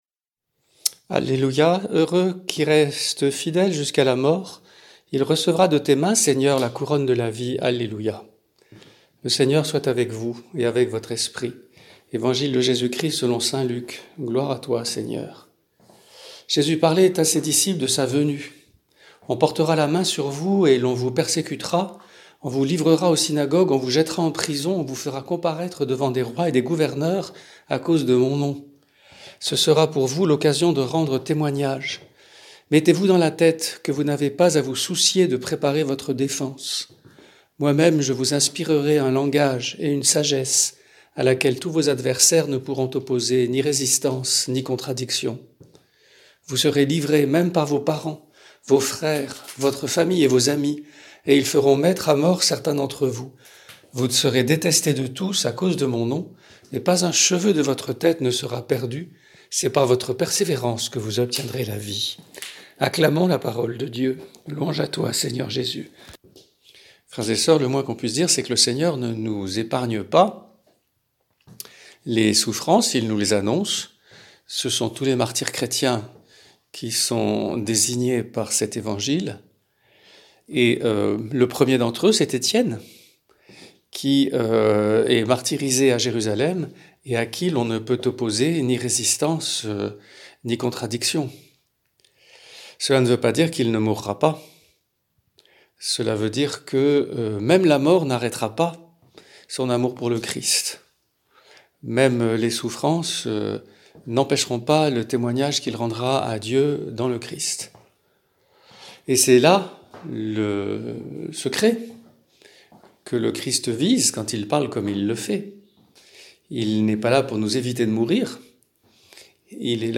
Homélie prononcée le 25 novembre 2020
Homélie